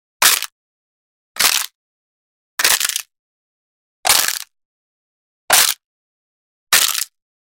SFX骨断裂小音效下载
SFX音效